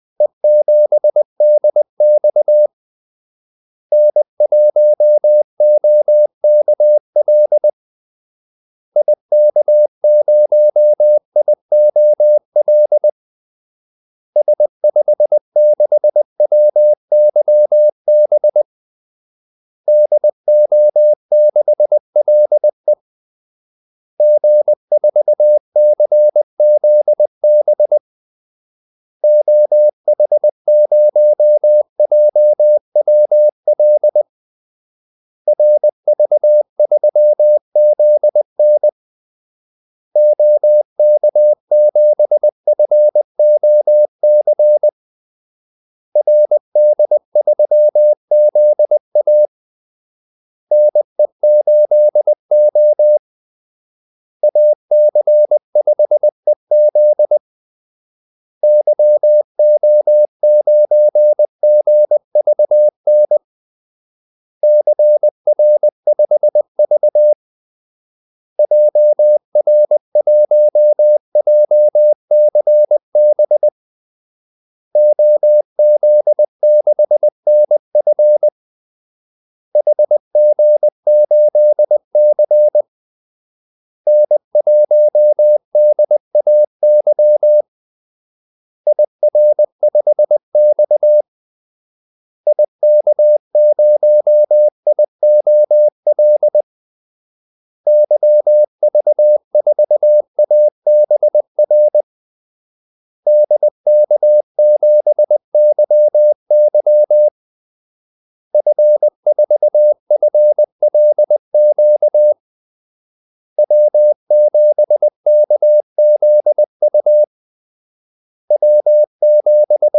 Kaldesignaler 20 wpm | CW med Gnister
Callsigns-20wpm.mp3